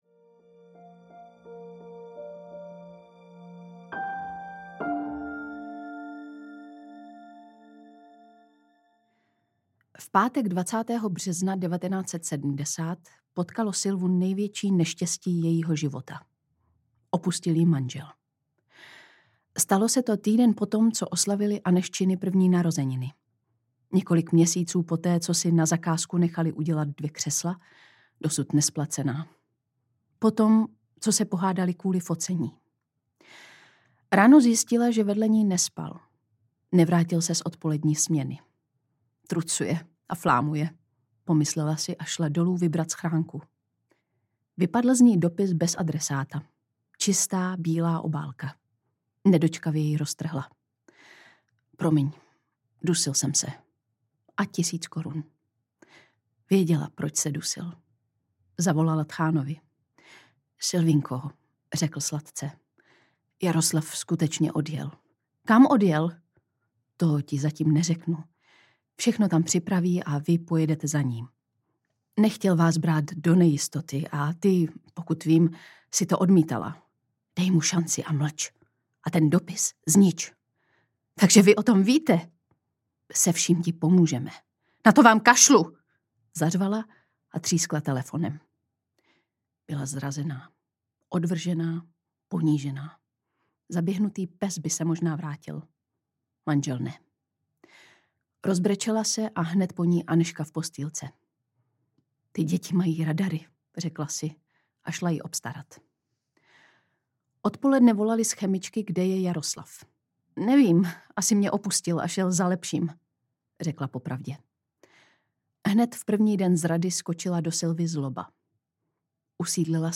Samoživitelka audiokniha
Ukázka z knihy
• InterpretLucie Vondráčková